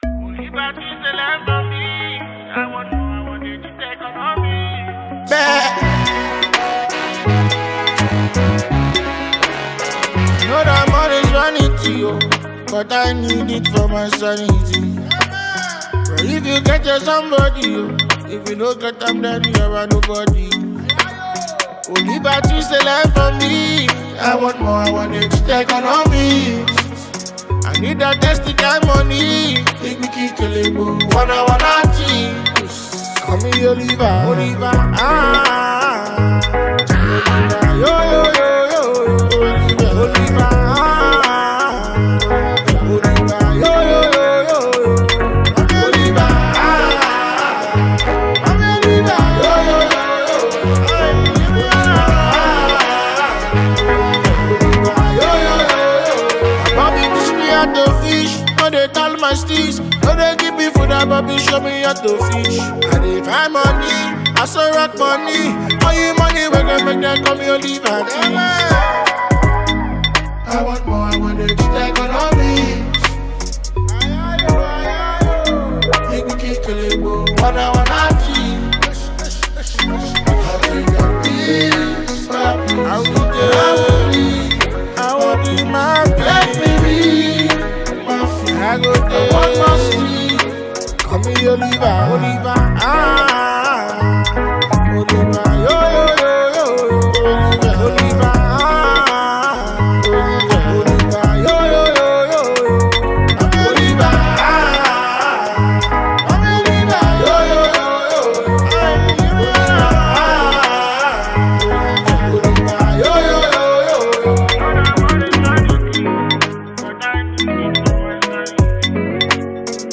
Known for blending Afro-pop, reggae, hip-hop, R&B, and soul